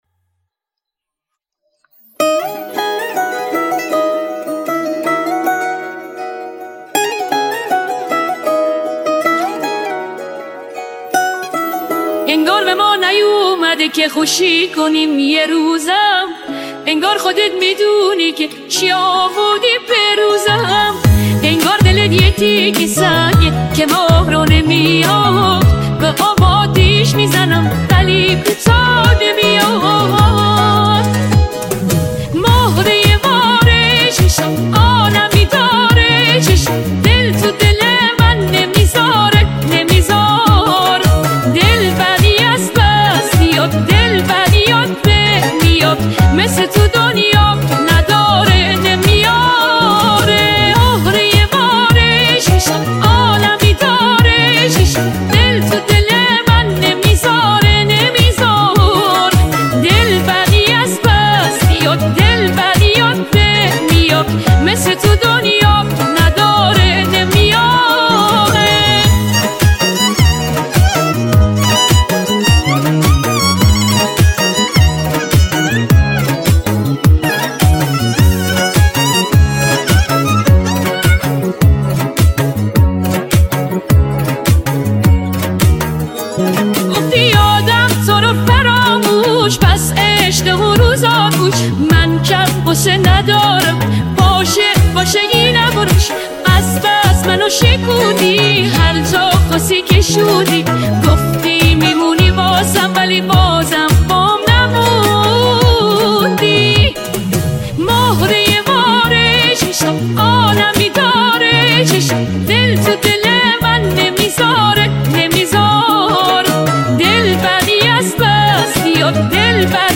پاپ شاد رقص عاشقانه